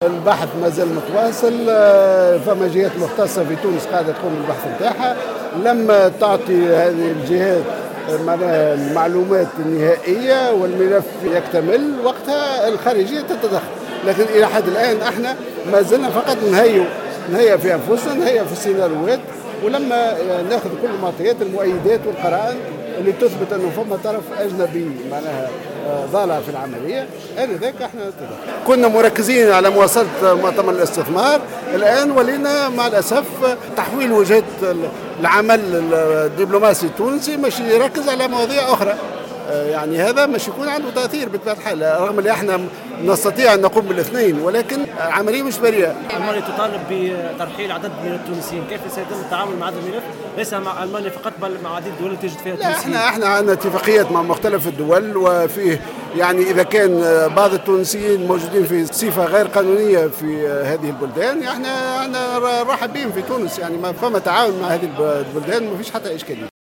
قال وزير الشؤون الخارجية خميّس الجهيناوي في تصريح